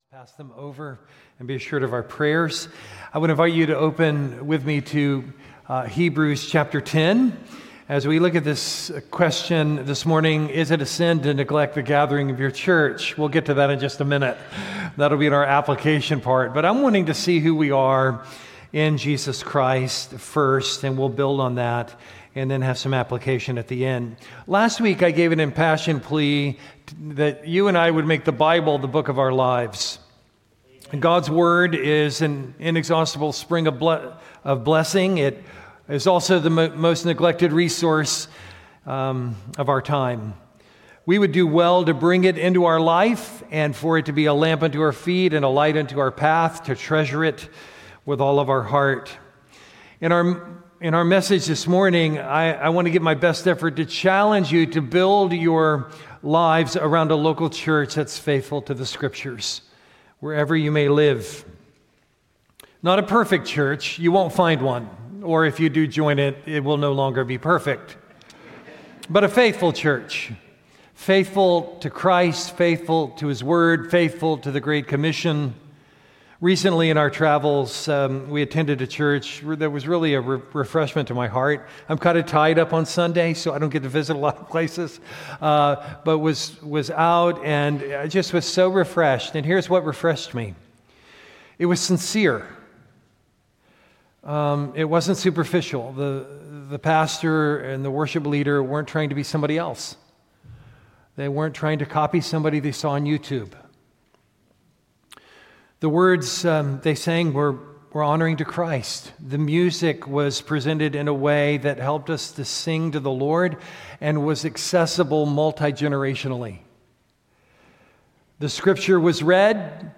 Sermon content from First Baptist Church Gonzales, LA